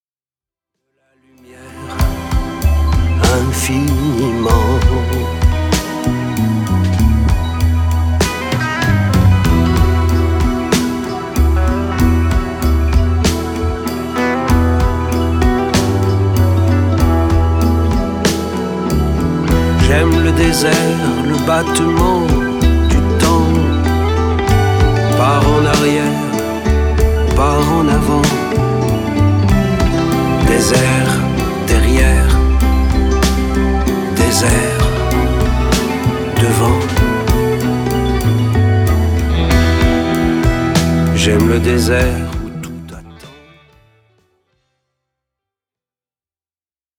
Sa voix qui chante et déclame nous interpelle.